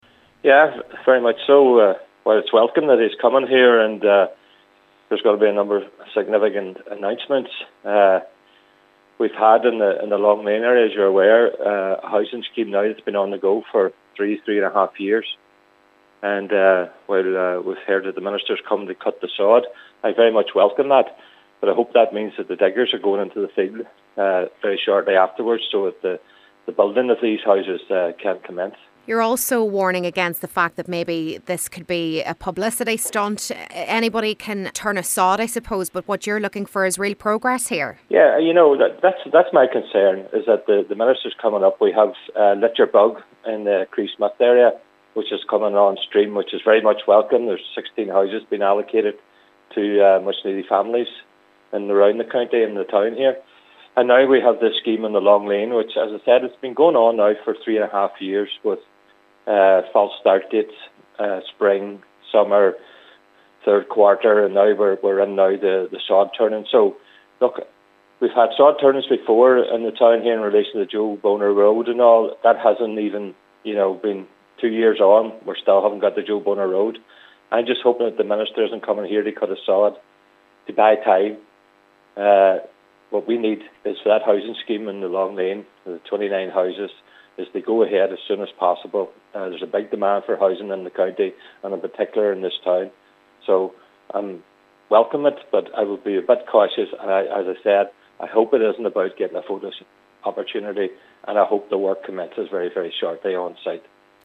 Cllr. Gerry McMonagle says while the Housing Ministers visit to Donegal is welcome, real progress must be made on the ground: